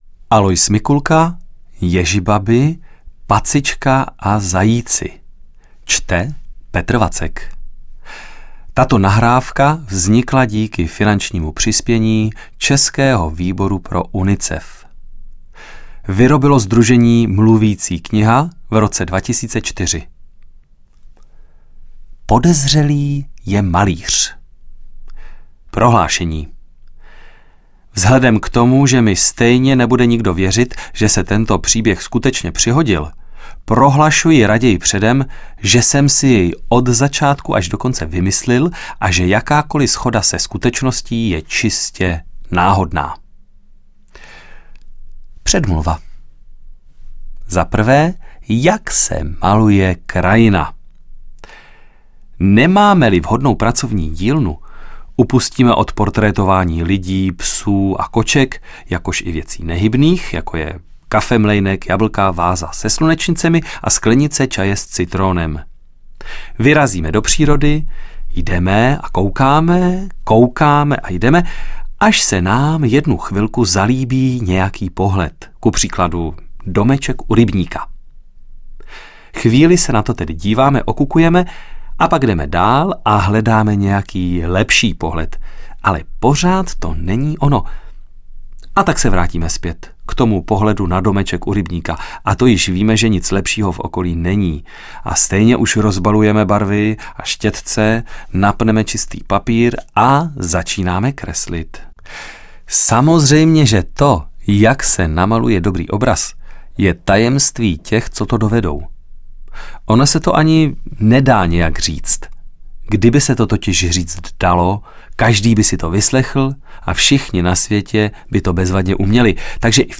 Mluvící kniha z.s.